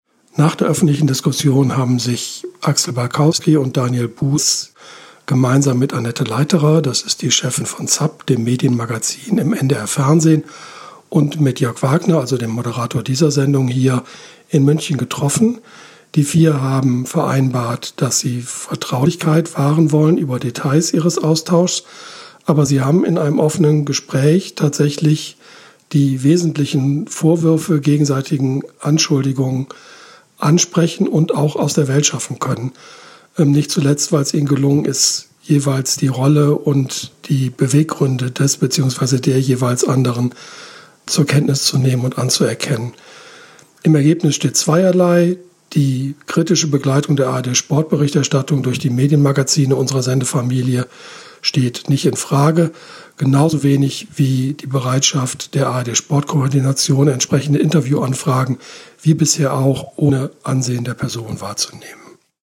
Statement von München
Was: Statement nach einer Aussprache in der ARD-Sportkoordination in Folge des Medienmagazins vom 09.06.2018